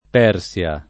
Persia [ p $ r SL a ]